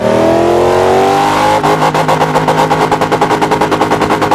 rev.wav